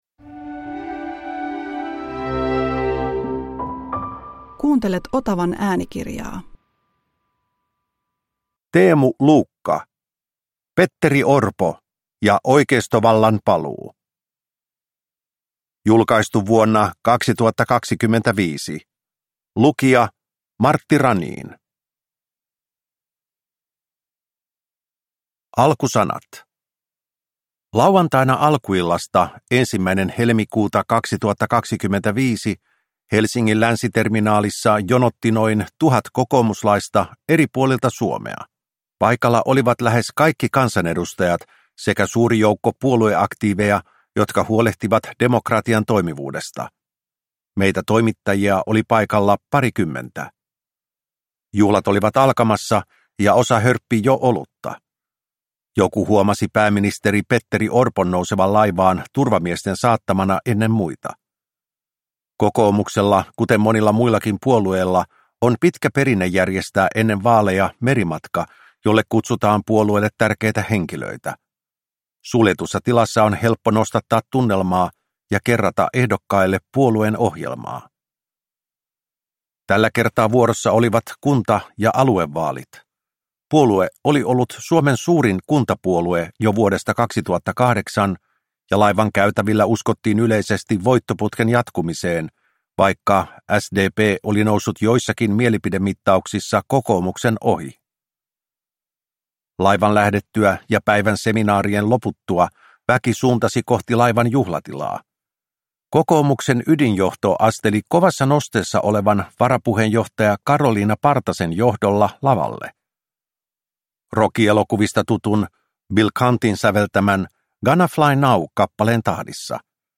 Petteri Orpo ja oikeistovallan paluu – Ljudbok